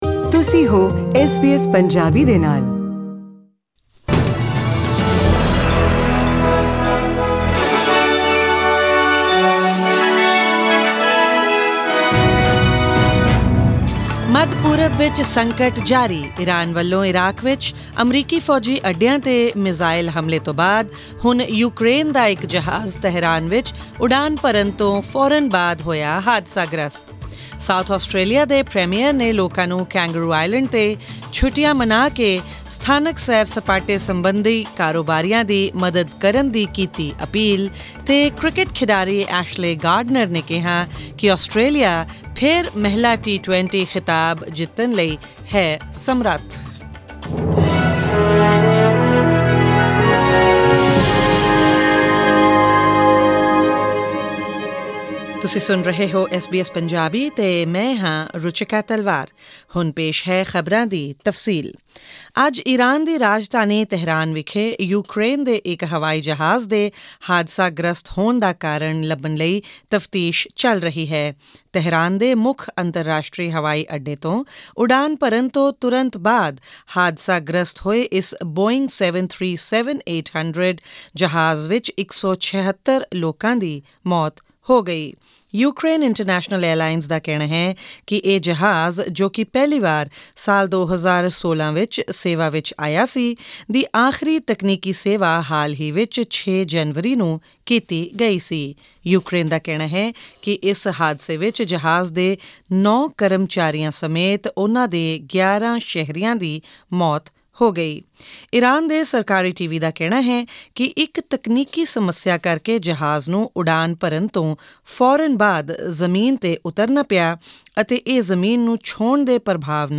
Presenting the major national and international news stories of today and updates on sports, currency exchange rates and the weather forecast for tomorrow.